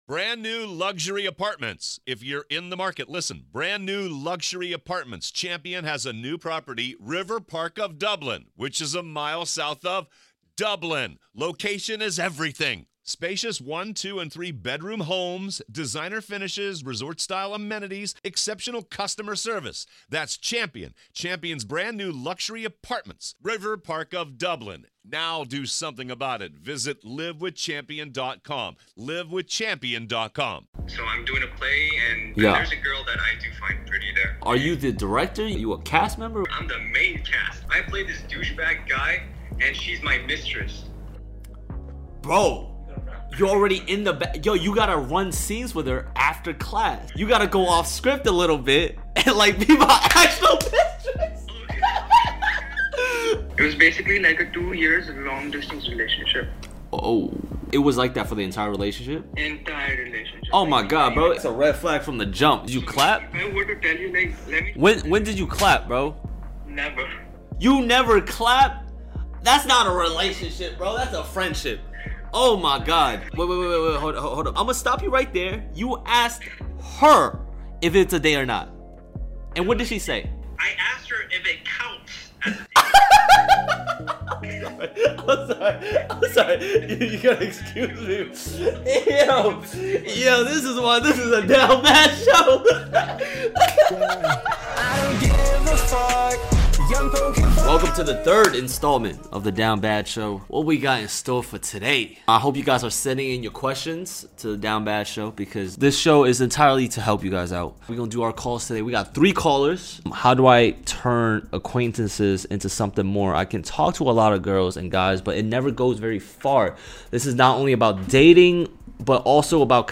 In today's Episode of the Down Bad Show, I teach a couple of guys on How to approach and talk to girls in your class, making your intentions clear and smooth. As well hearing out some down bad moments from today's callers.